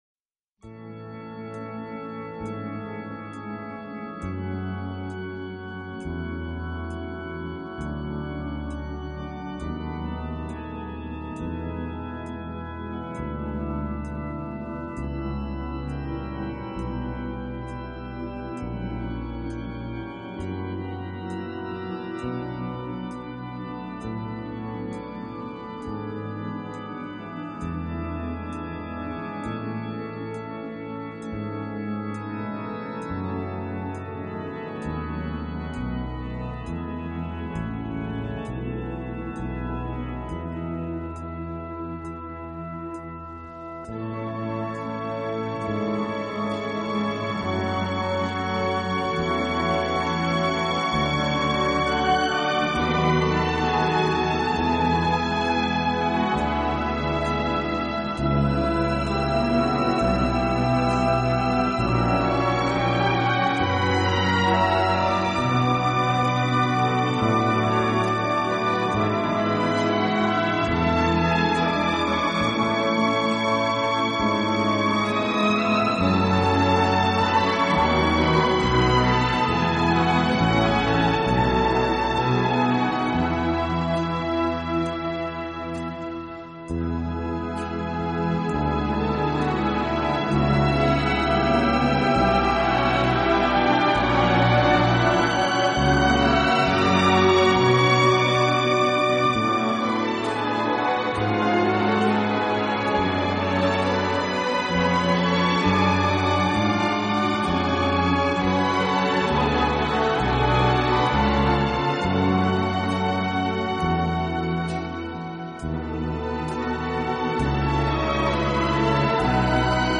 顶级轻音乐